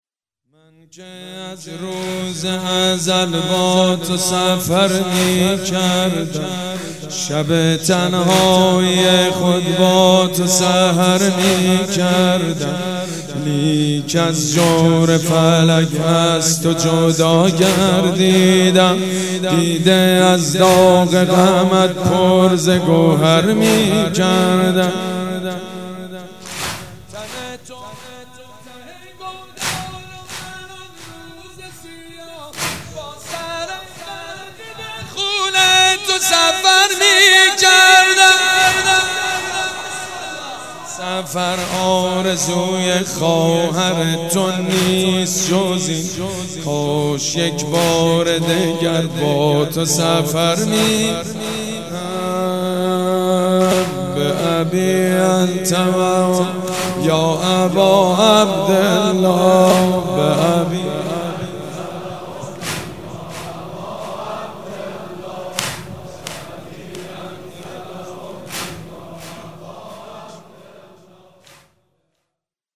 مداح
مراسم عزاداری شب اول